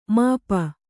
♪ māpa